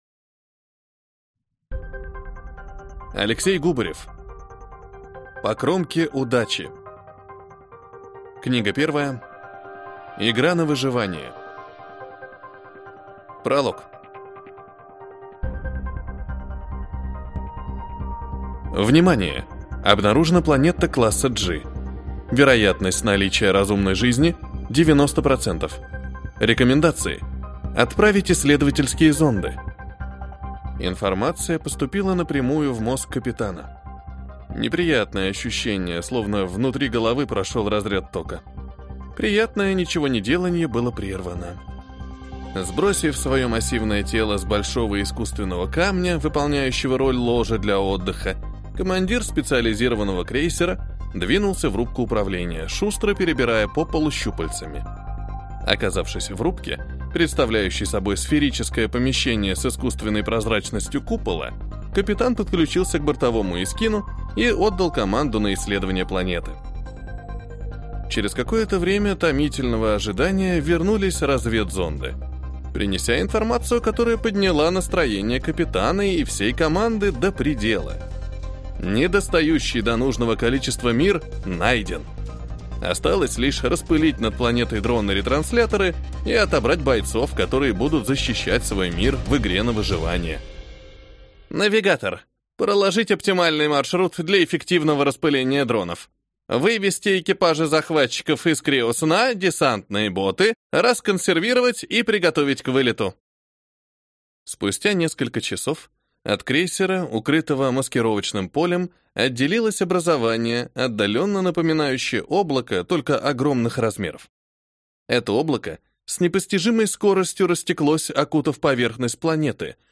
Аудиокнига По кромке удачи. Игра на выживание | Библиотека аудиокниг